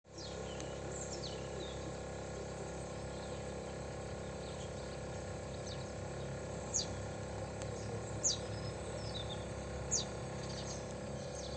J'ai enregistré le son en mode froid un quart d'heure après démarrage.
Dans les deux cas, chaud et froid, j'entends plus un bruit de claquement moins normale que le premier enregistrement.
bruit-mode-froid-apres-demarrage-1.mp3